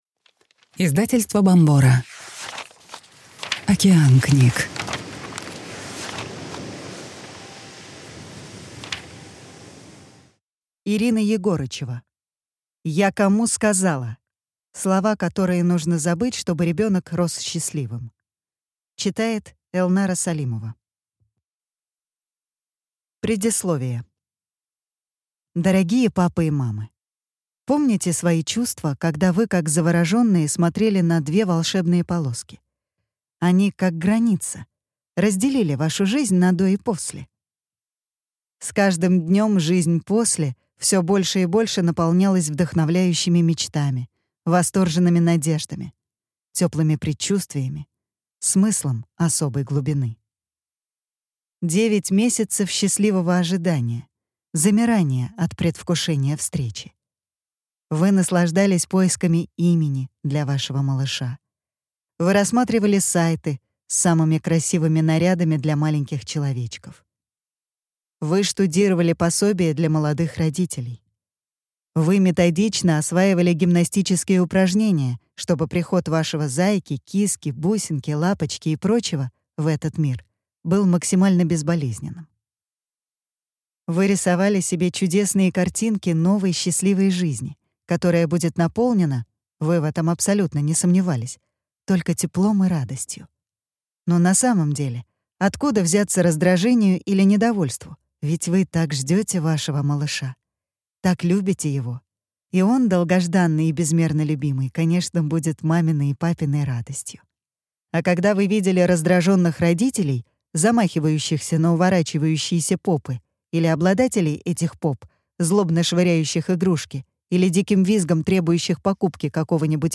Аудиокнига Я кому сказала! Слова, которые нужно забыть, чтобы ребенок рос счастливым | Библиотека аудиокниг